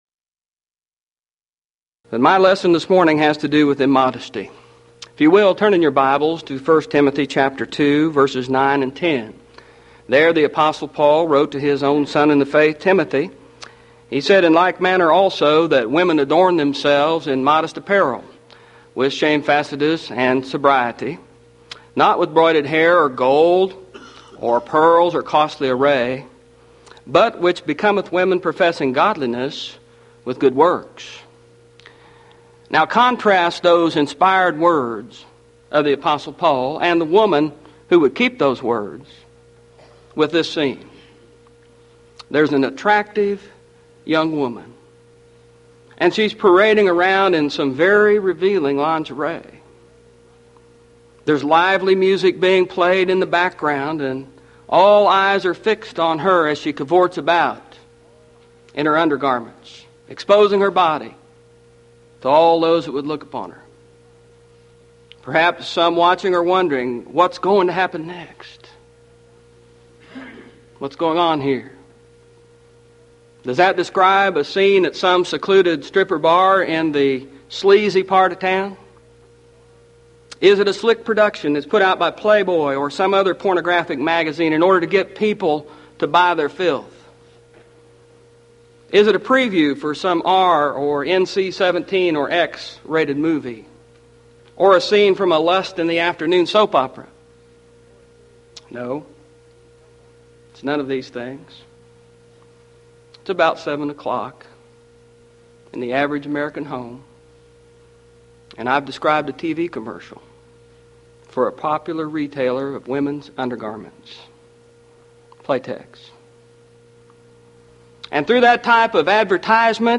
Event: 1993 Mid-West Lectures
lecture